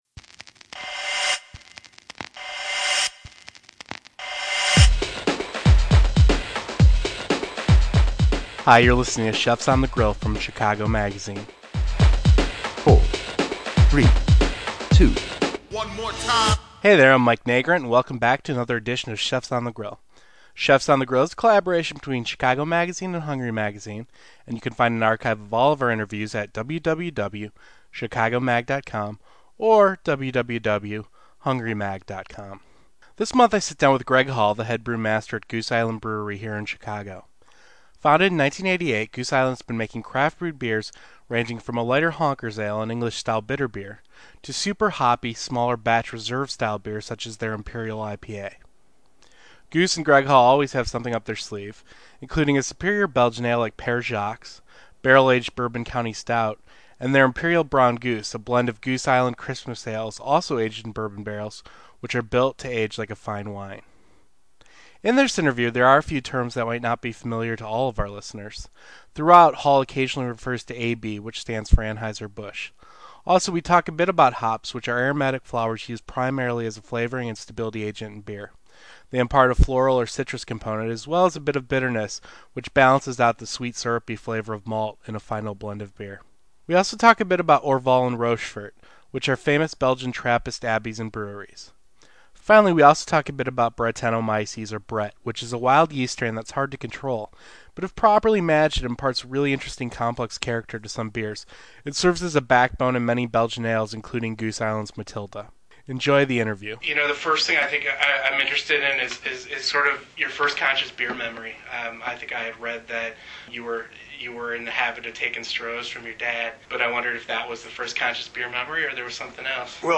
Enjoy this month’s podcast interview over a glass of suds.